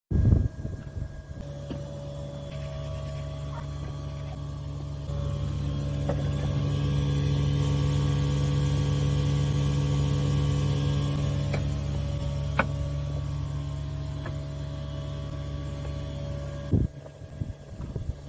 Le groupe est fixé au mur et son bruit est très présent à l'extérieure et même assez perceptible à l'intérieur de la maison.
Son unité extérieure mp3
- le compresseur qui ronronne (en général, c'est plutôt discret)
- le fluide qui circule
- une sorte de sifflement.
Le tout mélangé, on a l'impression que quelqu'un passe l'aspirateur à côté !
son-unite-exterieure.mp3